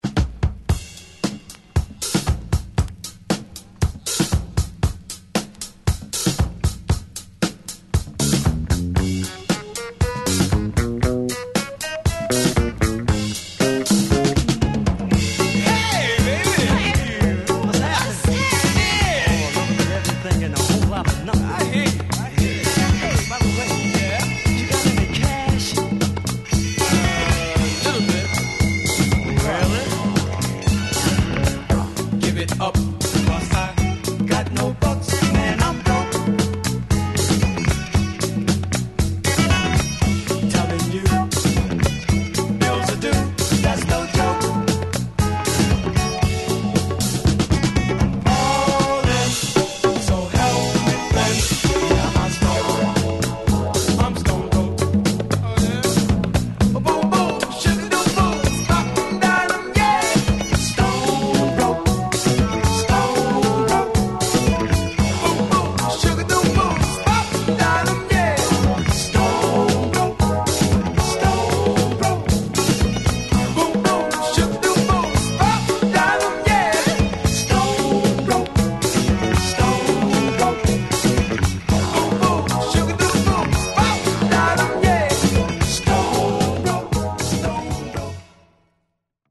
頭にDrum Break!!